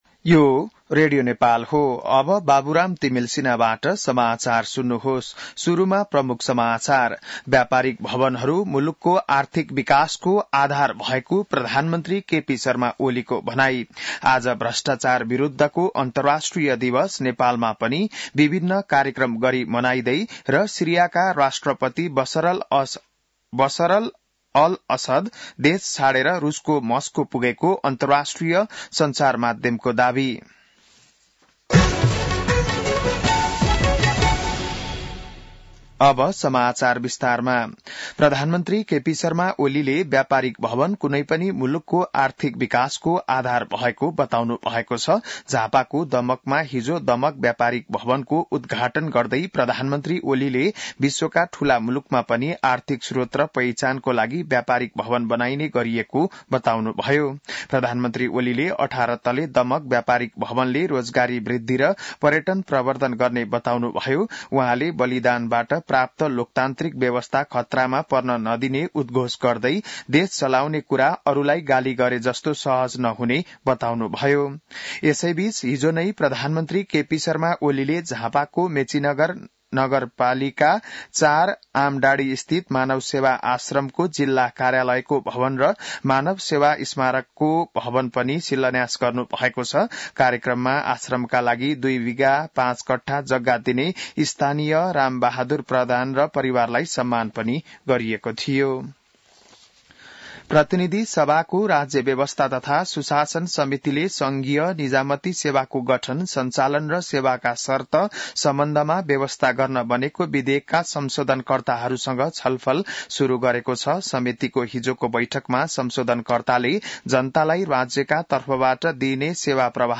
बिहान ९ बजेको नेपाली समाचार : २५ मंसिर , २०८१